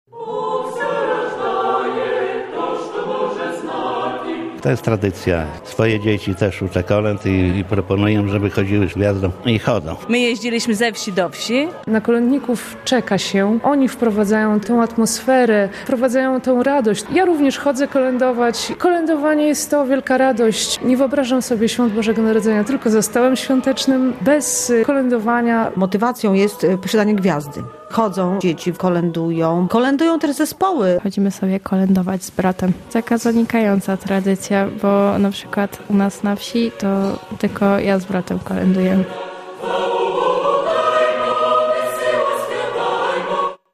W wielu podlaskich miejscowościach z bogatym repertuarem kolęd wyruszają grupy kolędnicze - niosą kolorową gwiazdę i śpiewają tradycyjne kolędy w kilku językach.